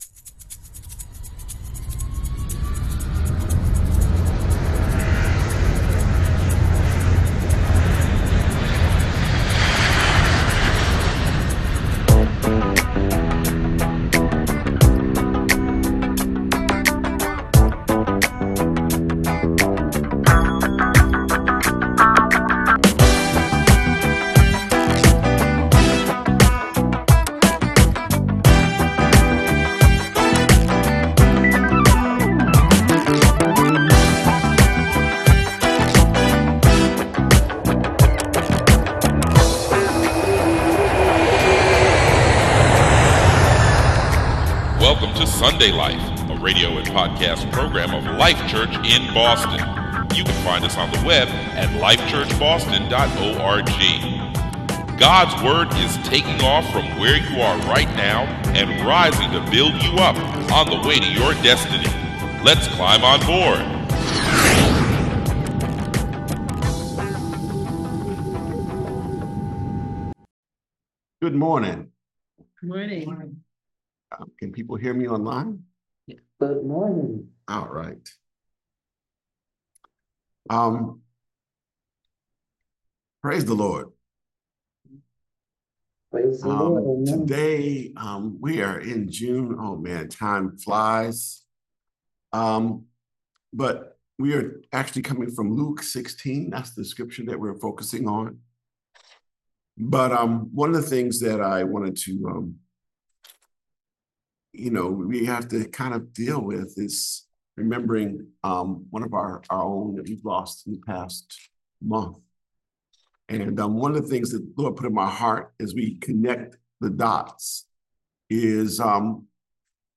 Sunday Life (sermon podcast) - Life Church